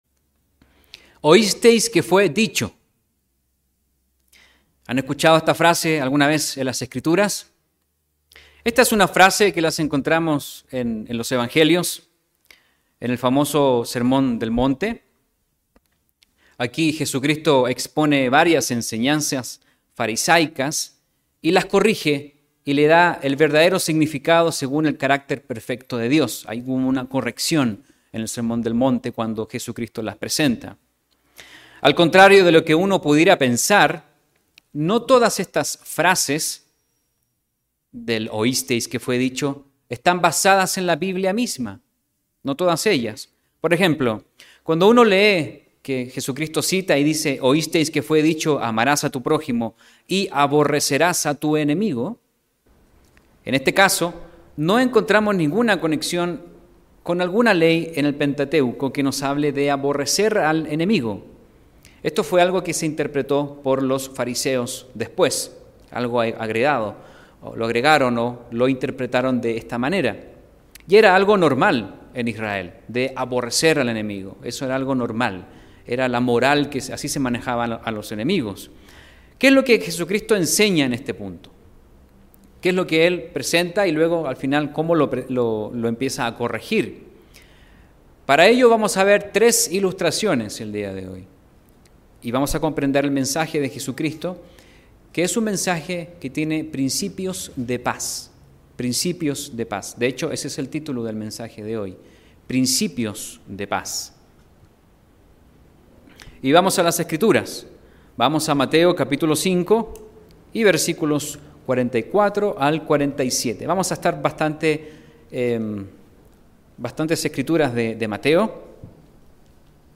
Sin embargo, la Biblia contiene sabiduría divina para la vida cotidiana. ¿Cómo convertirnos en agentes de paz? Mensaje entregado el 6 de noviembre de 2021.